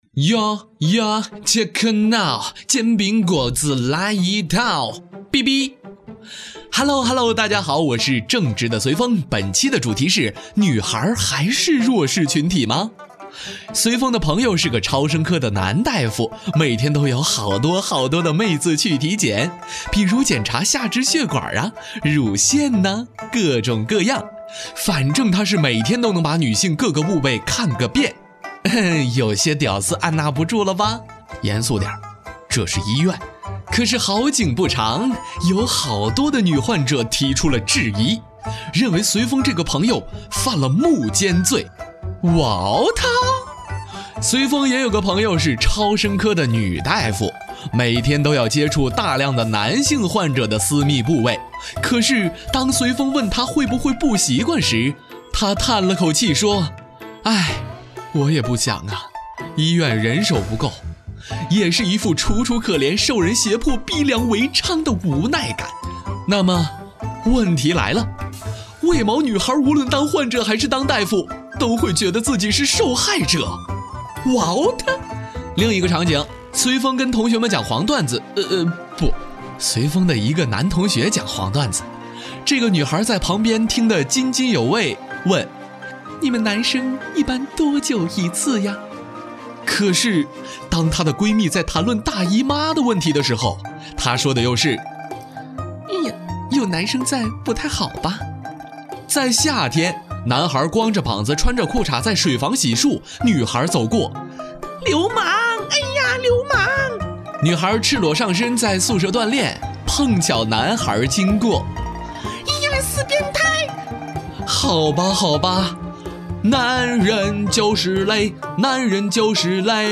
特点：年轻自然 素人 走心旁白
29男-电台-女孩还是弱势群体么.mp3